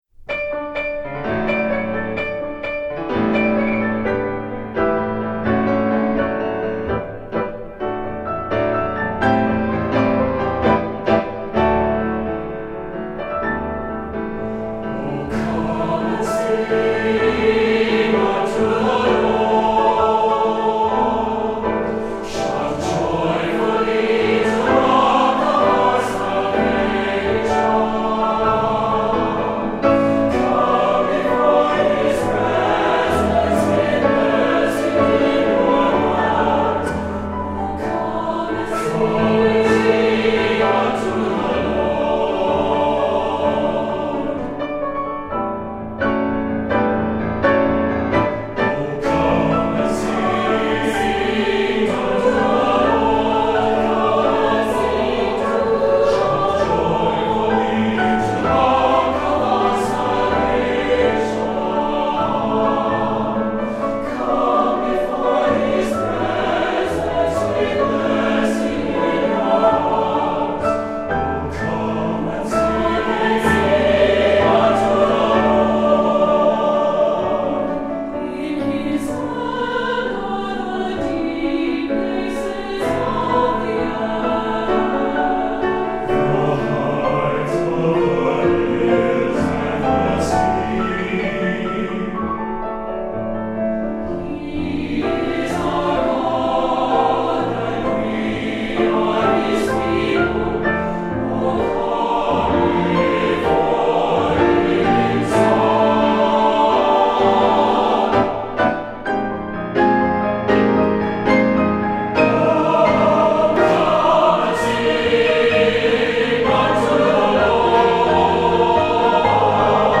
Voicing: SATB and 4 Hand Piano